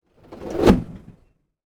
WHOOSH_Steam_Fast_02_mono.wav